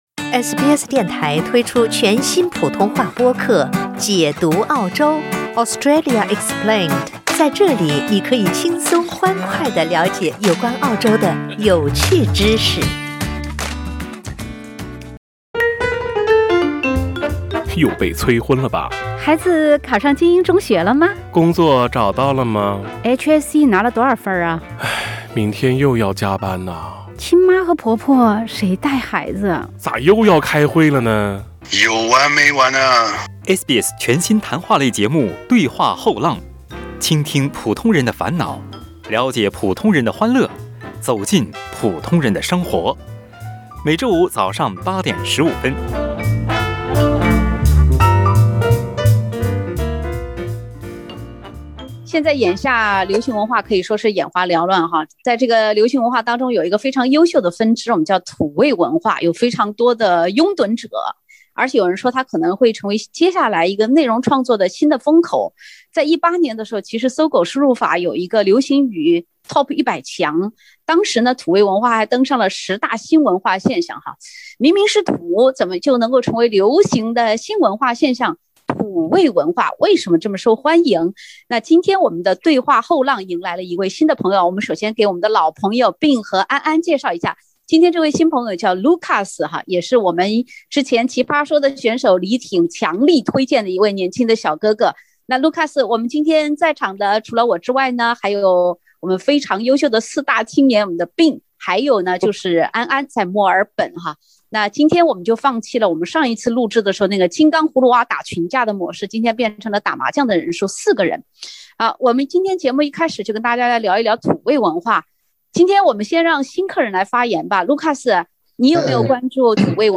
当今流行文化现象中还有一个谜一般存在的“优秀”分支-“土味文化”。（点击封面图片，收听“后浪”有趣谈话）